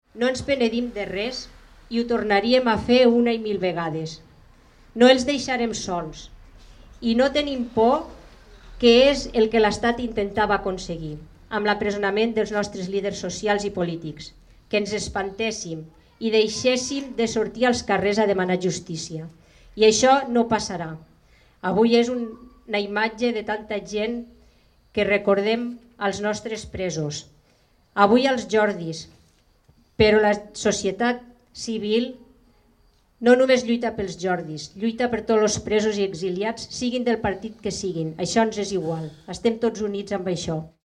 Uns discursos que van ser fortament aplaudits amb crits de “llibertat”. També tots els presents van interpretar els Segadors.